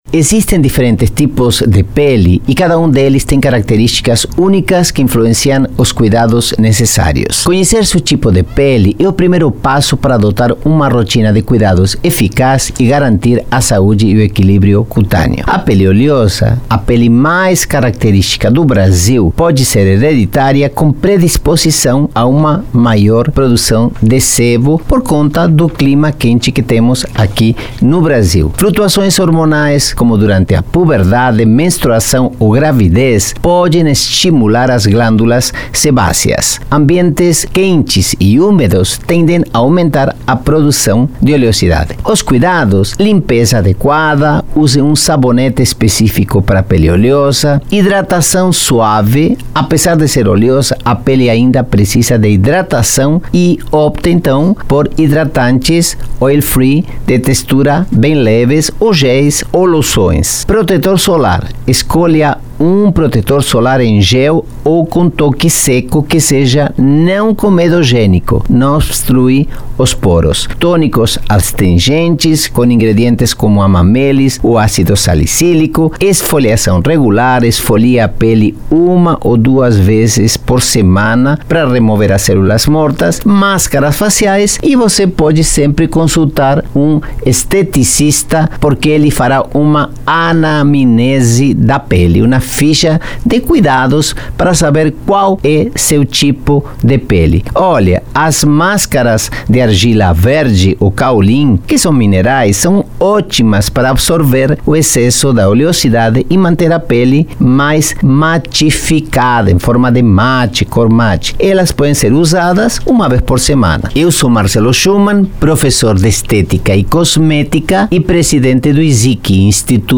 Categoria: Coluna
Periodicidade: 3 vezes por semana (segunda, quarta e sexta), gravada